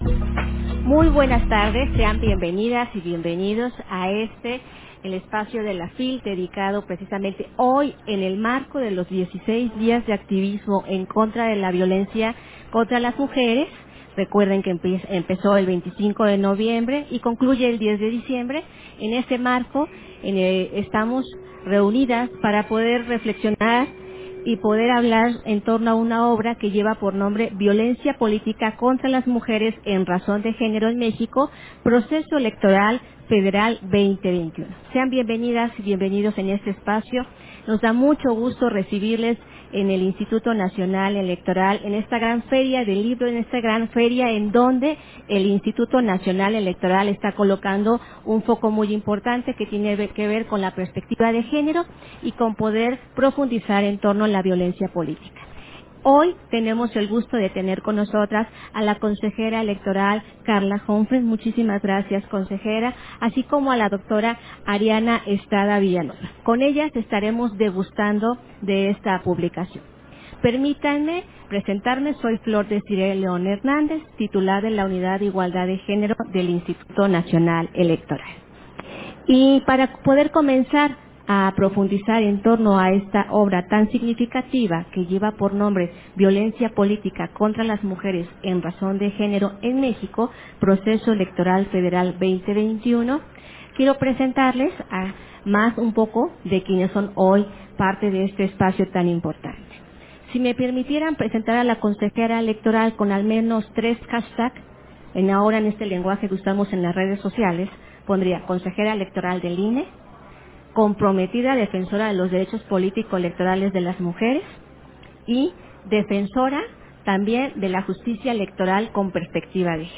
Versión estenográfica de la presentación editorial, Violencia política contra las mujeres en razón de género en México: Proceso Electoral Federal 2020-2021, FIL Guadalajara 2024